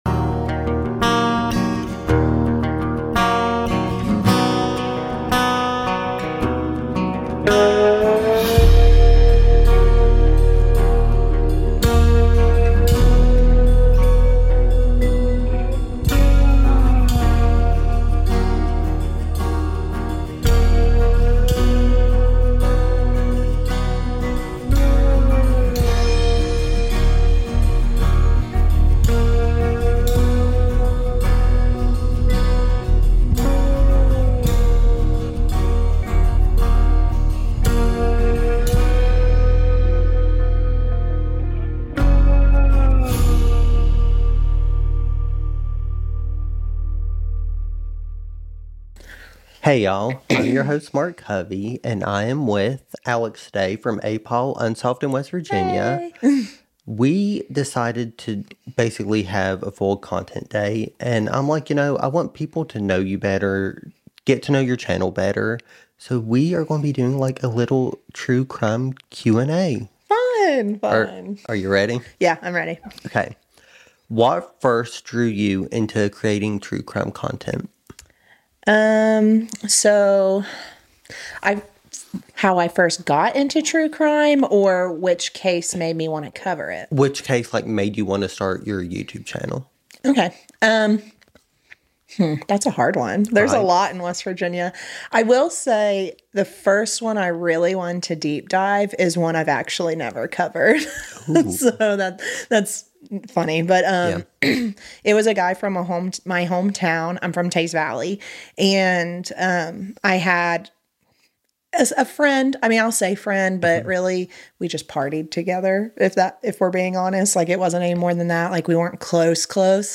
The Interview Files - APAUL UNSOLVED IN WV